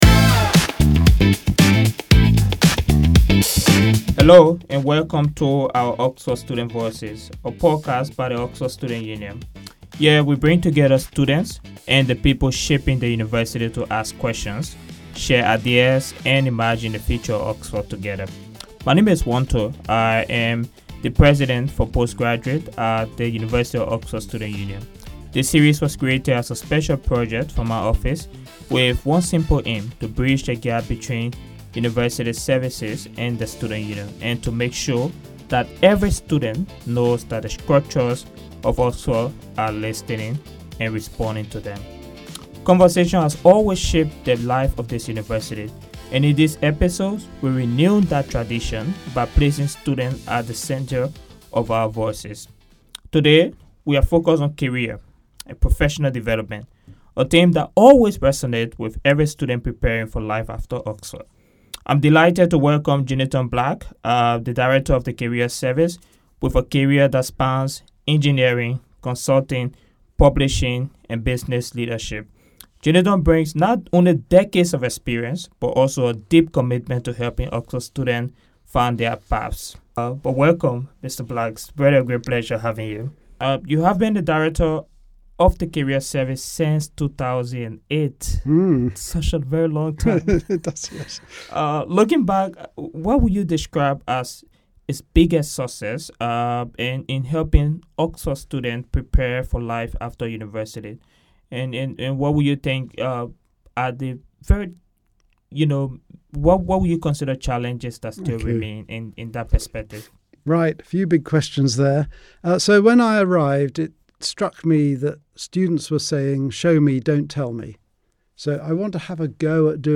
The conversation explores employability in an age of AI, the value of internships and hands-on programmes such as the Oxford Strategy Challenge, and the support available to international students navigating new job markets.